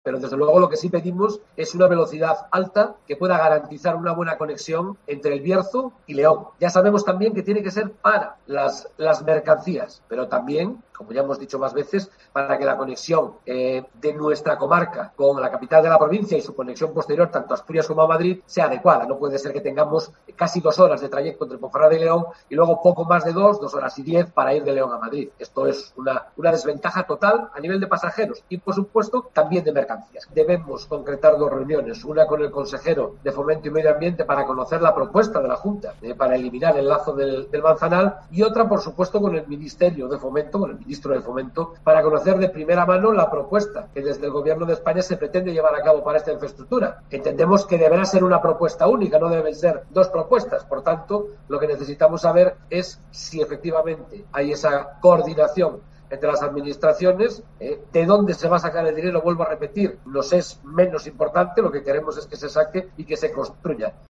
AUDIO: Escucha aquí a Gerardo Álvarez Courel, presidente del Consejo Comarcal del Bierzo